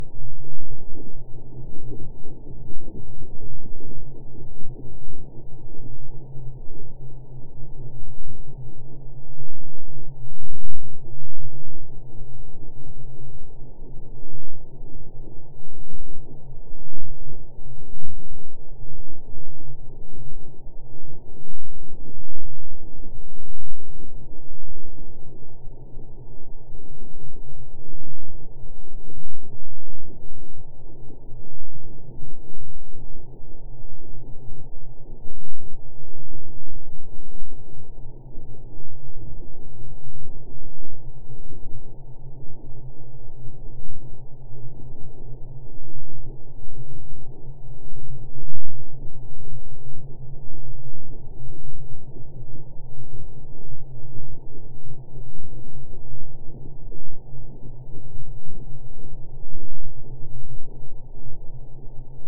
To get an acoustic sense of the birds’ frenzy, I slowed a sound recording of the hummingbird in the photograph by one hundred times. The heart-beat thuds are the slowed wings, the strange wind is the sound of insects singing in techno-molasses:
hummingbirdonehundred.mp3